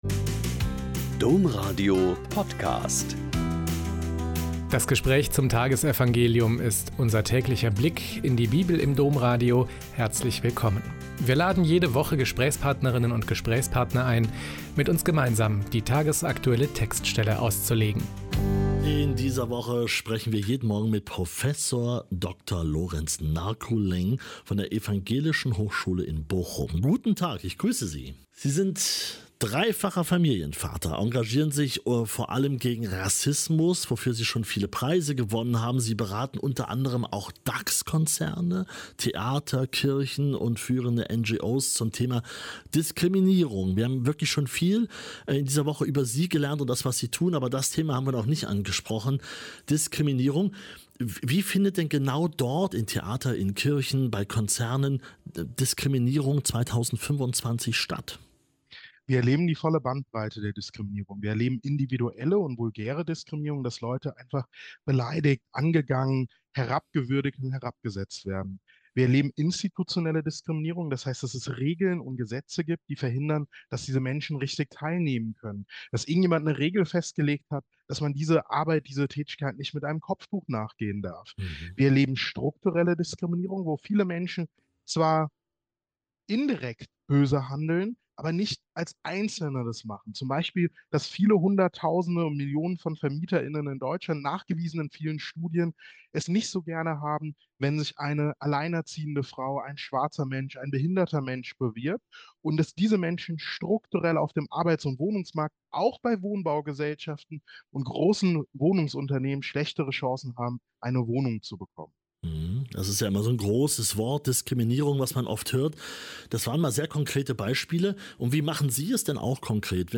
Lk 5,1-11 - Gespräch